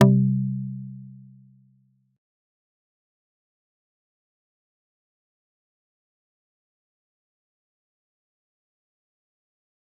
G_Kalimba-C3-pp.wav